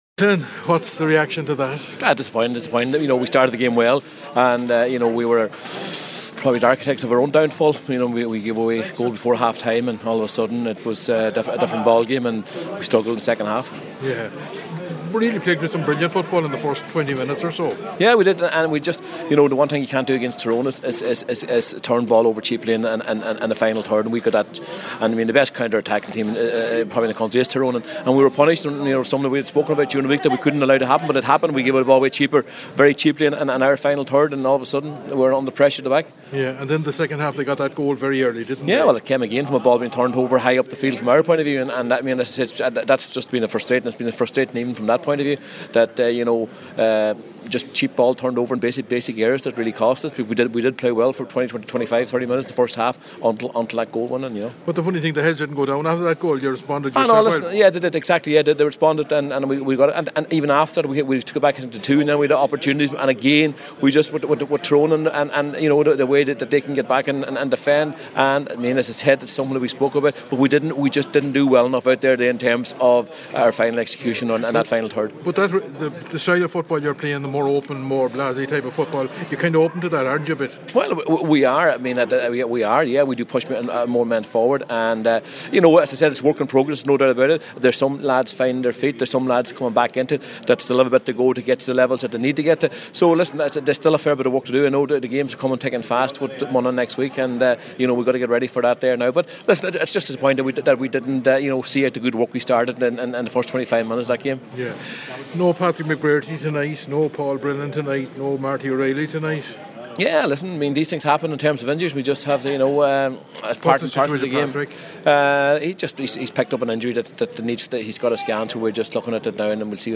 After the match, Donegal manager Declan Bonner spoke